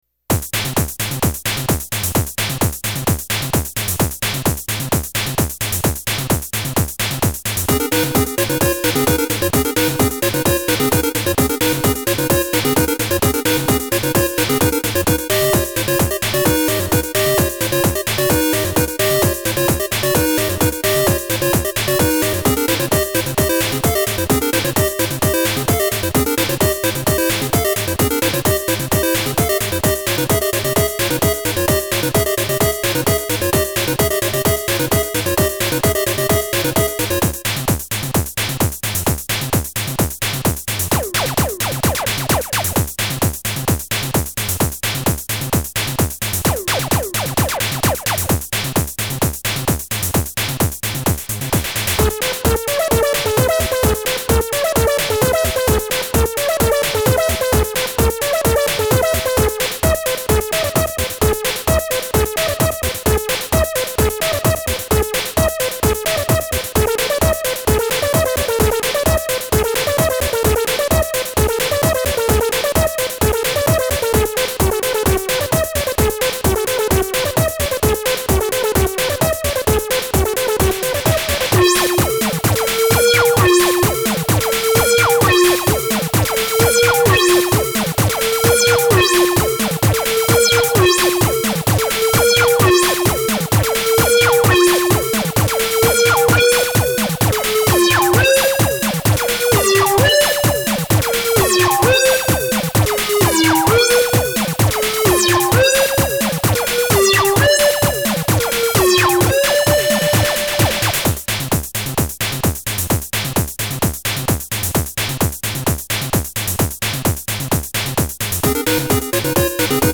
DS-10は私の想像をはるかに超えた「オールインワンシンセ」でした。